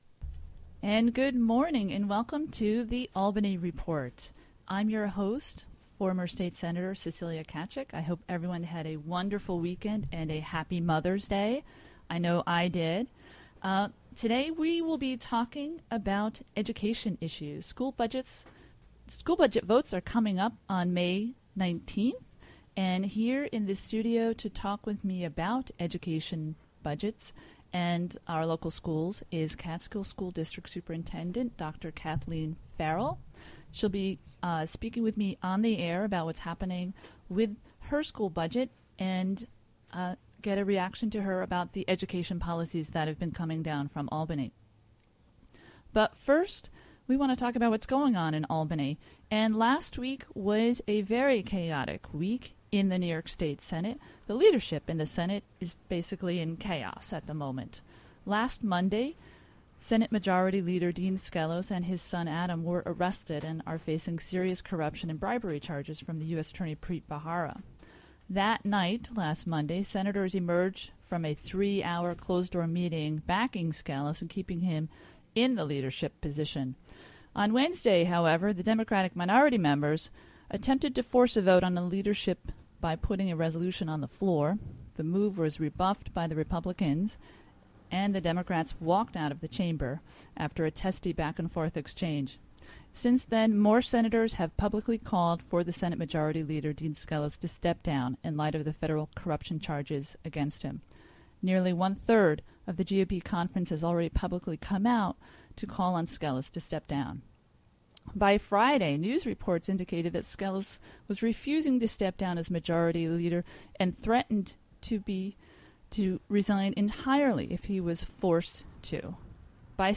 Hosted by Cecilia Tkaczyk.